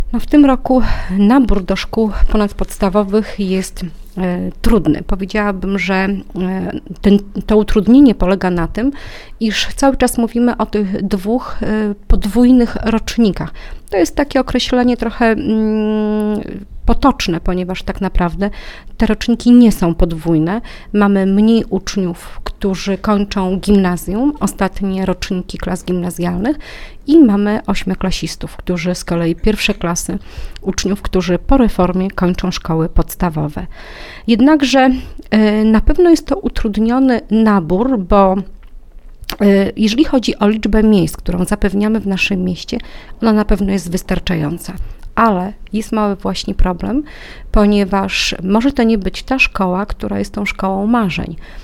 O szczegółach mówi Ewa Sidorek, zastępca prezydenta Suwałk.